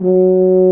BRS F TUBA09.wav